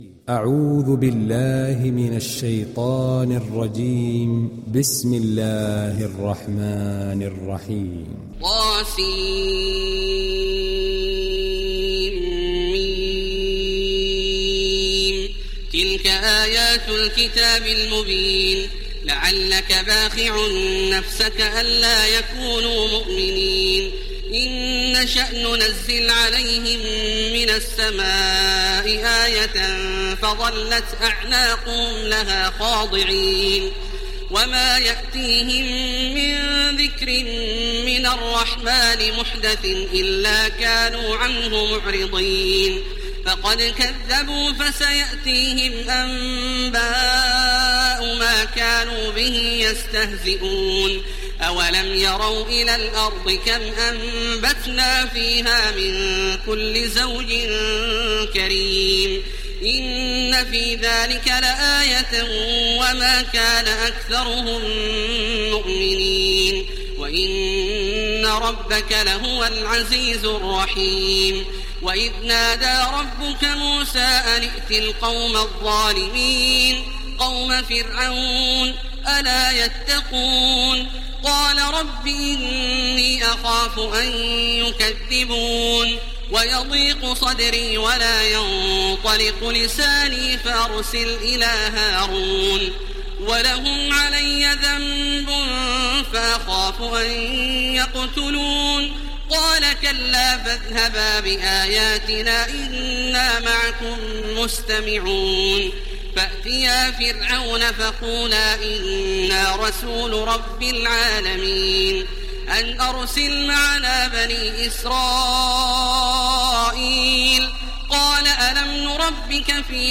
دانلود سوره الشعراء mp3 تراويح الحرم المكي 1430 روایت حفص از عاصم, قرآن را دانلود کنید و گوش کن mp3 ، لینک مستقیم کامل
دانلود سوره الشعراء تراويح الحرم المكي 1430